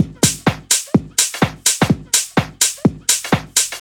• drum loop house breaks.wav
drum_loop_house_breaks_QL7.wav